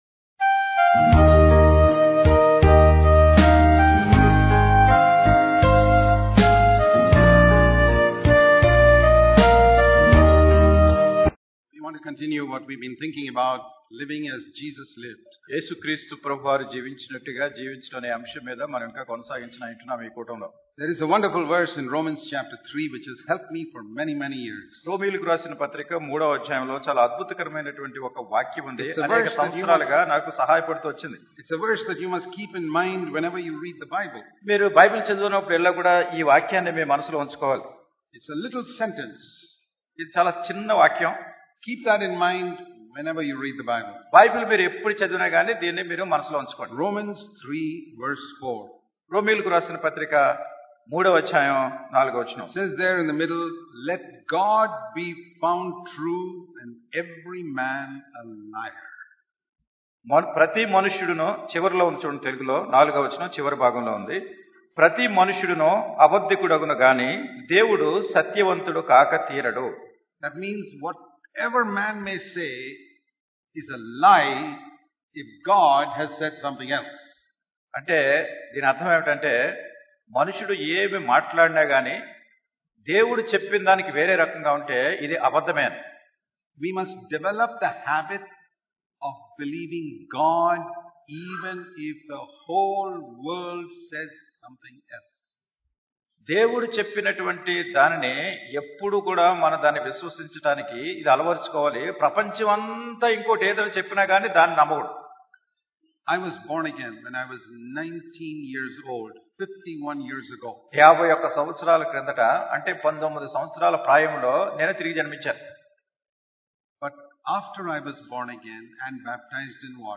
Sermons in this Series